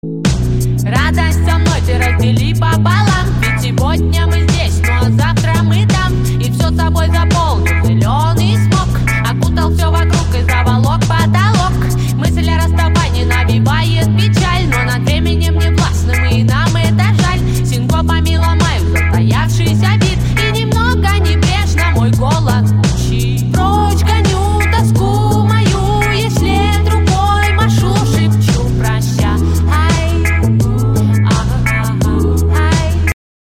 • Качество: 128, Stereo
спокойные
релакс
регги